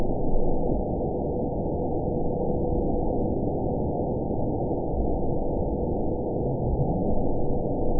event 920295 date 03/12/24 time 23:12:23 GMT (1 year, 1 month ago) score 7.95 location TSS-AB07 detected by nrw target species NRW annotations +NRW Spectrogram: Frequency (kHz) vs. Time (s) audio not available .wav